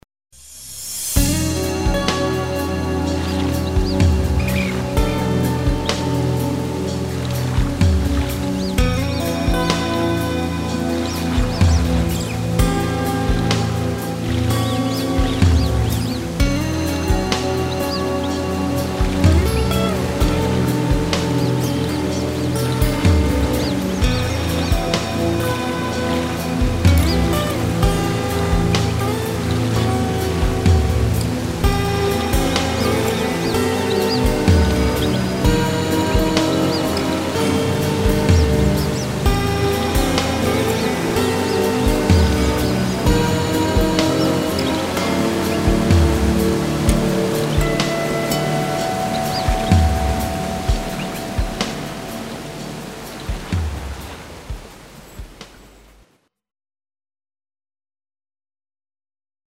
Scene #9 - Hypnotic melodies on acoustic guitar.